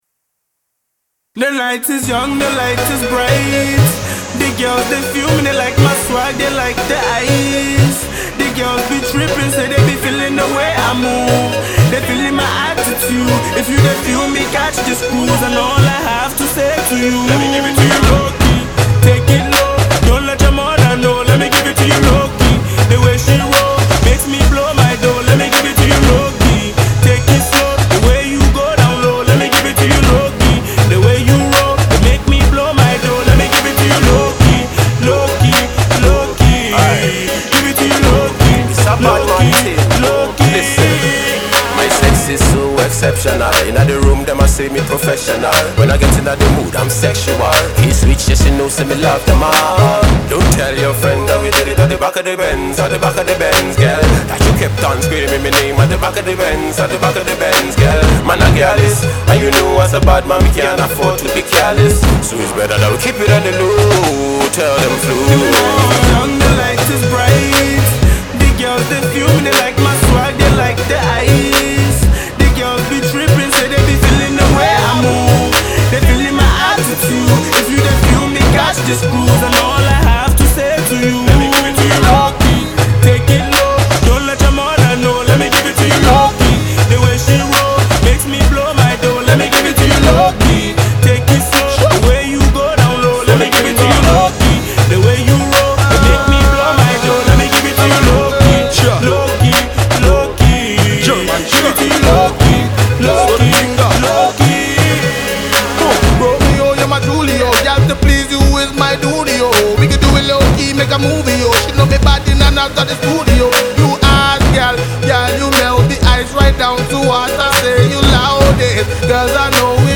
I just knew it’ll be a banging tune.